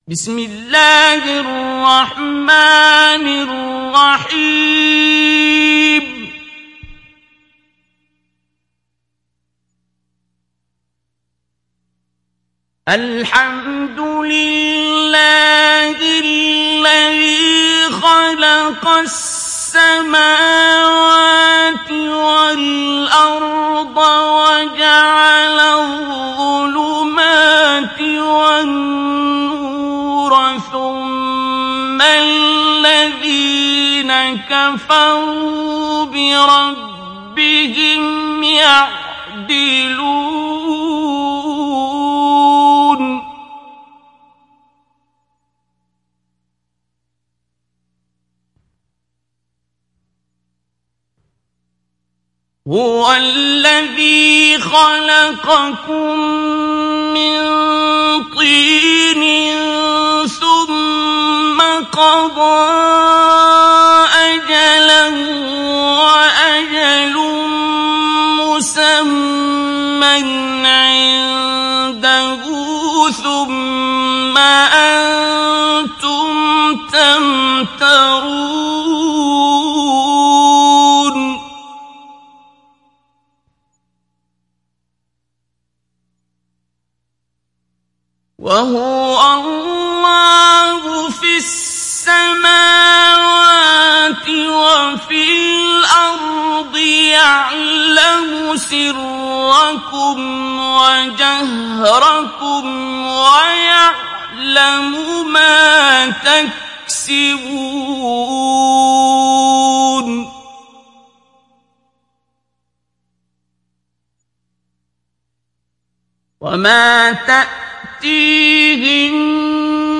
تحميل سورة الأنعام mp3 بصوت عبد الباسط عبد الصمد مجود برواية حفص عن عاصم, تحميل استماع القرآن الكريم على الجوال mp3 كاملا بروابط مباشرة وسريعة
تحميل سورة الأنعام عبد الباسط عبد الصمد مجود